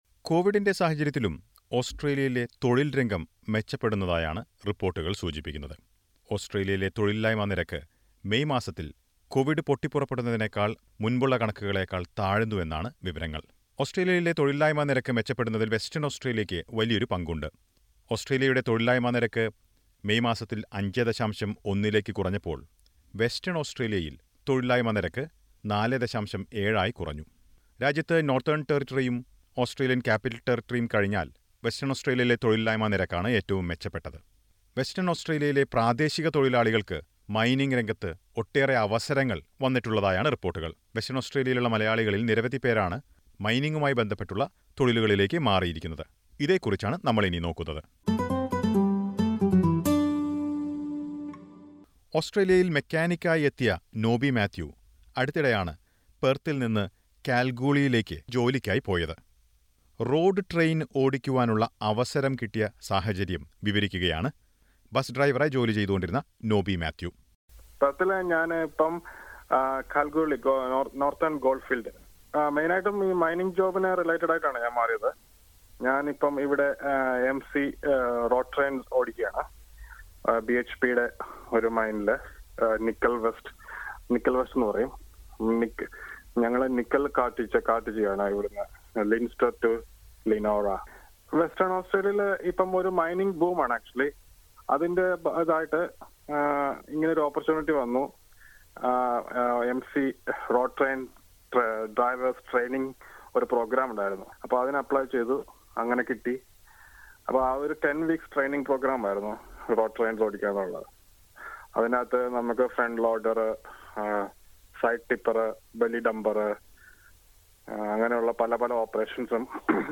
Many WA residents are making the best of the shortage of workers in the mining sector. Several people are landing into high paid jobs in the state's mining sector. Listen to a report.